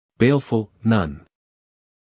英音/ ˈbeɪlf(ə)l / 美音/ ˈbeɪlf(ə)l /